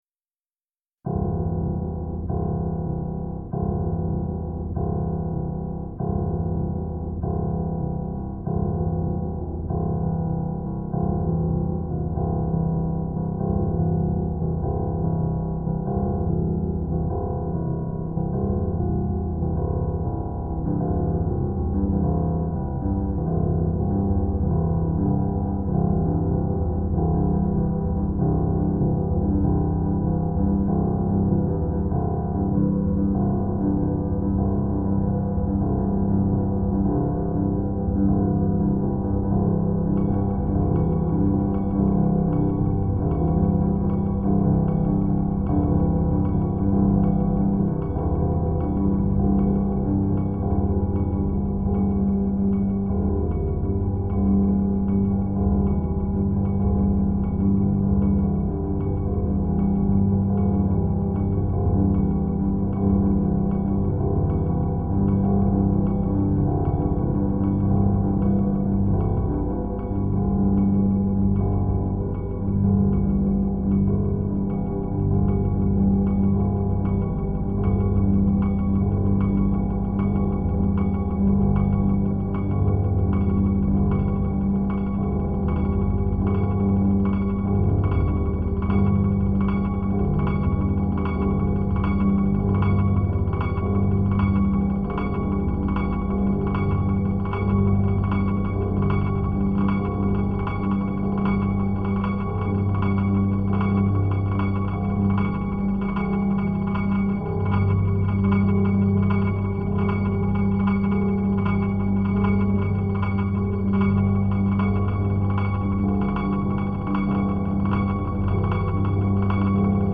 Piano_Water_Minimal_8.mp3